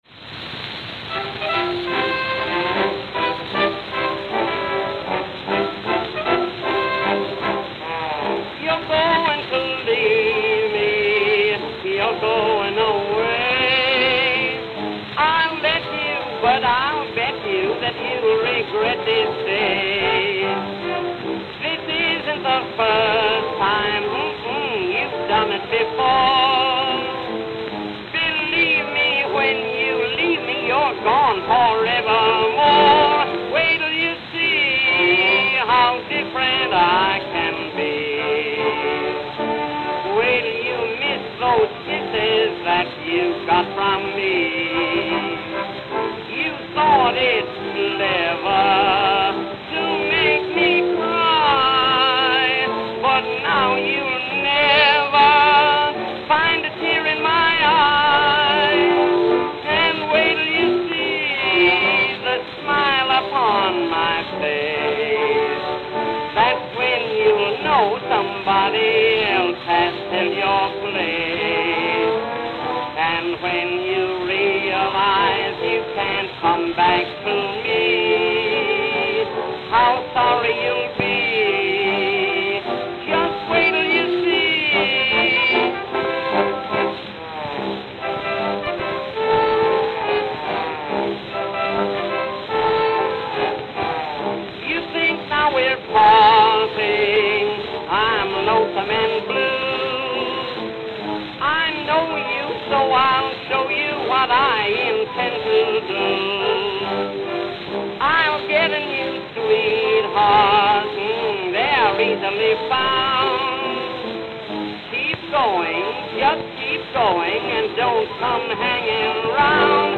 Note: Played at 83 RPM.